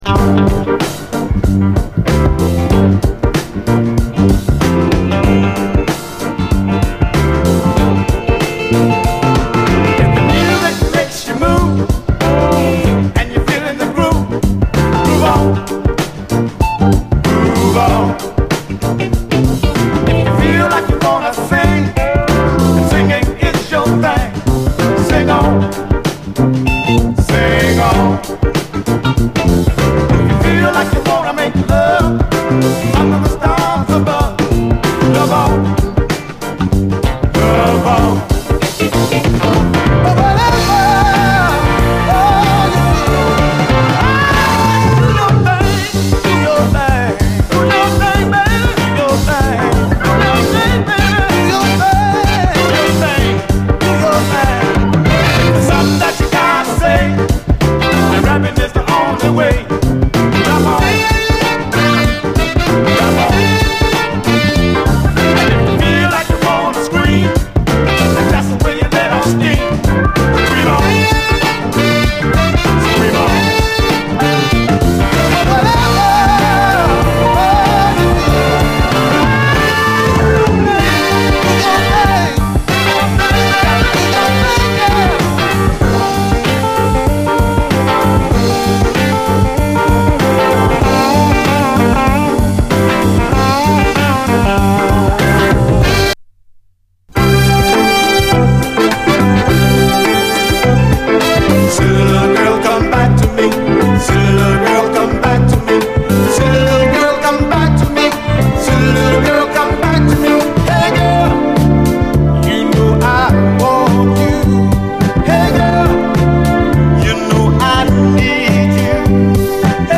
SOUL, 70's～ SOUL
レアグルーヴ感がほとばしる、
陽気な２ステップ・ソウル